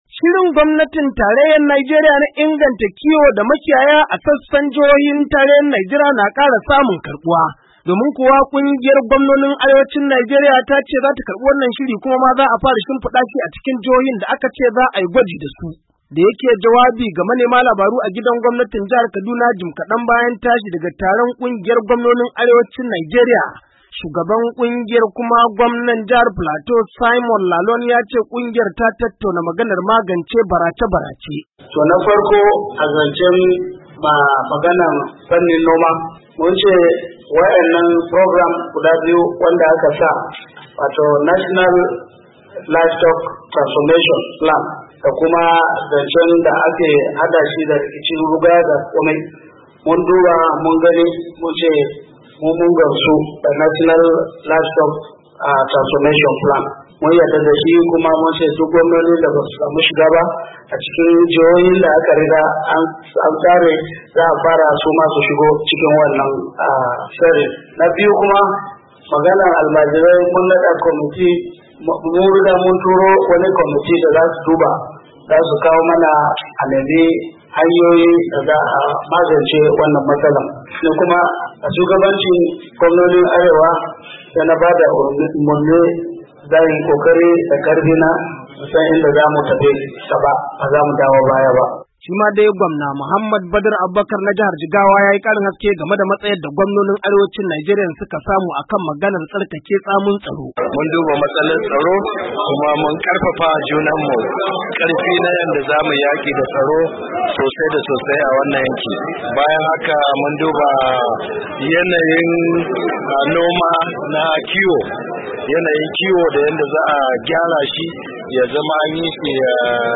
Taron Gwamnonin Arewacin Najeriya A Jihar Kaduna